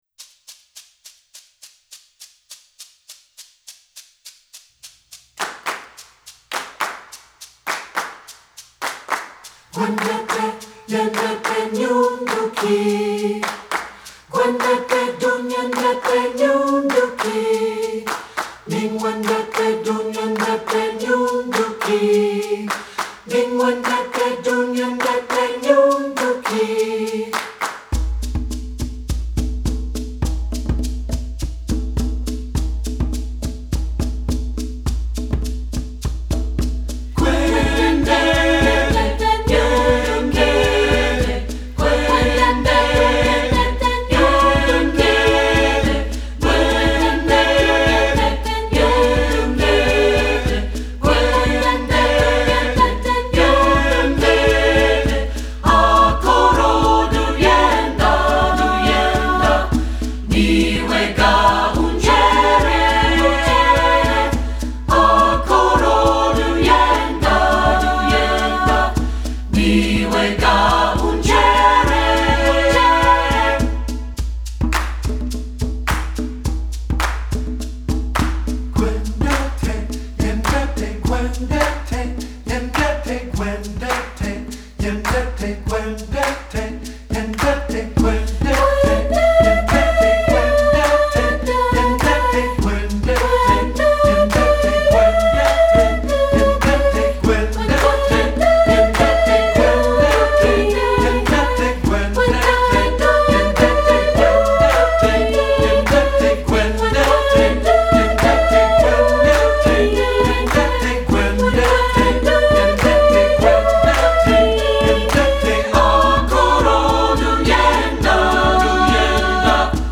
Voicing: 3-Part Mixed a cappella